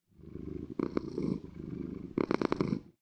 purr2.ogg